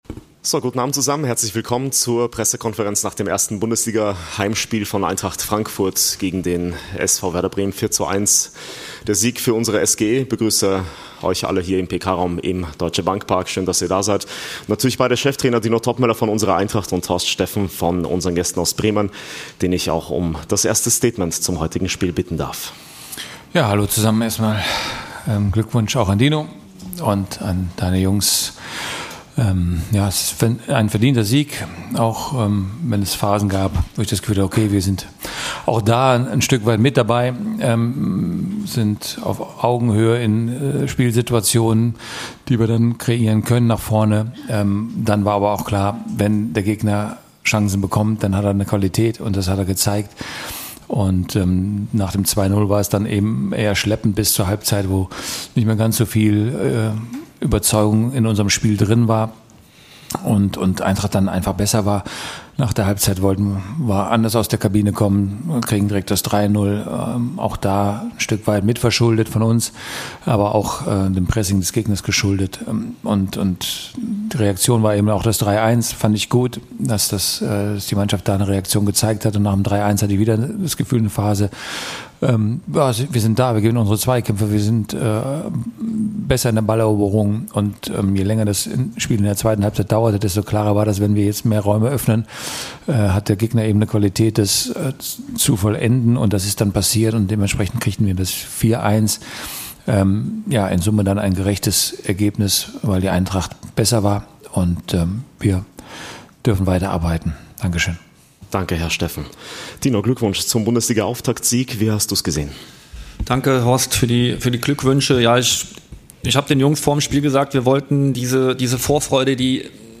Die Pressekonferenz mit den beiden Cheftrainern Dino Toppmöller und Horst Steffen nach dem Heimspiel gegen den SV Werder Bremen.